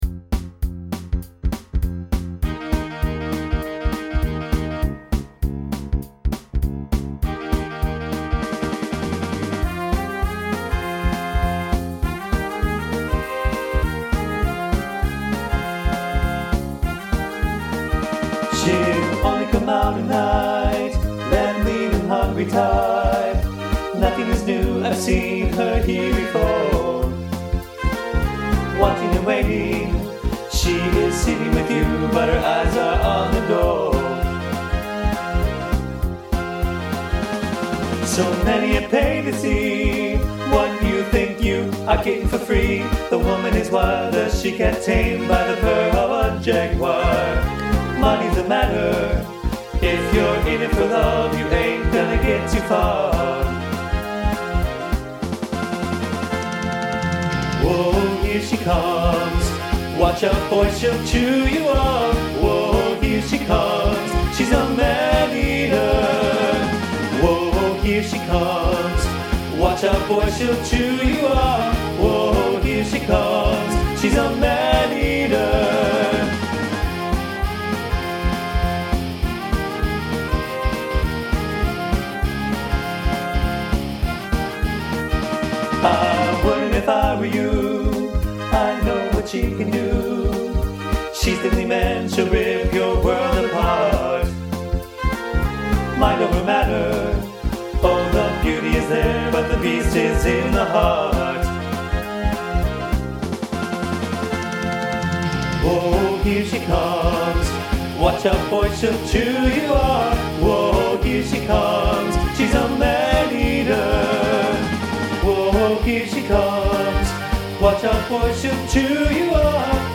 Voicing TTB Instrumental combo Genre Pop/Dance , Rock